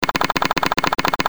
cartoon8.mp3